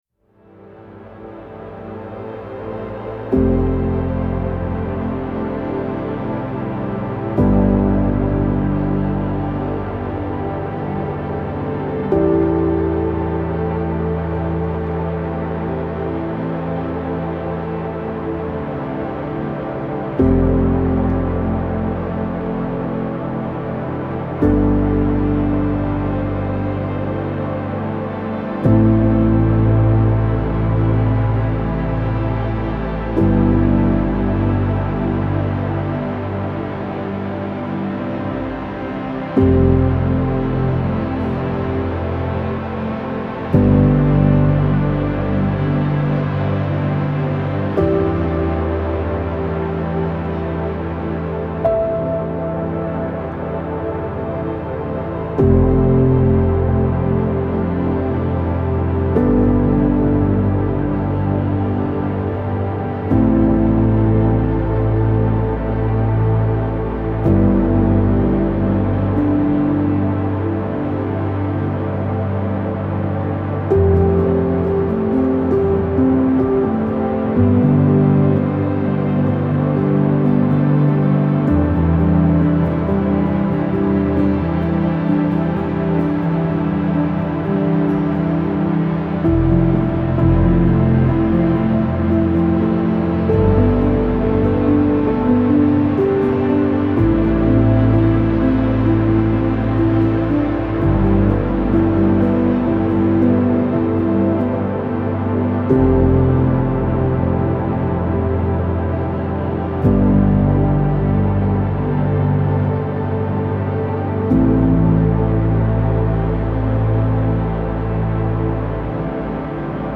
موسیقی بی کلام امبینت موسیقی بی کلام تامل برانگیز